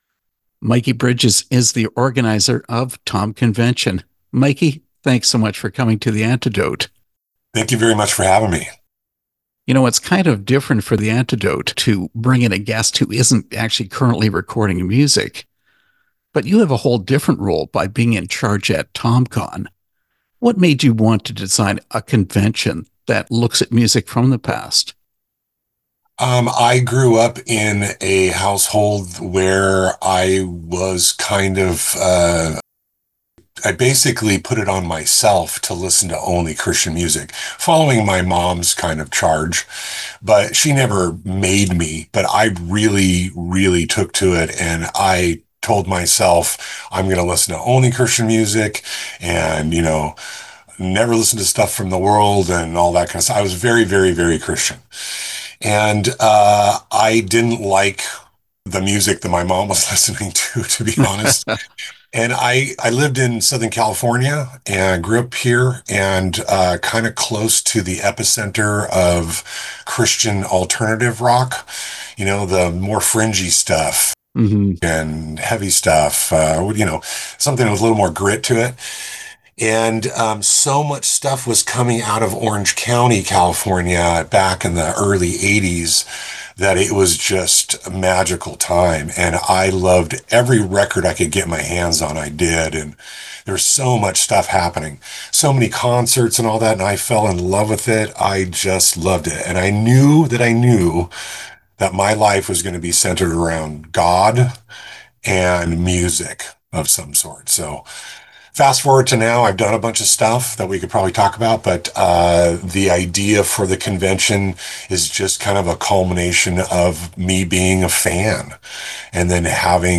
tomcon-interview.mp3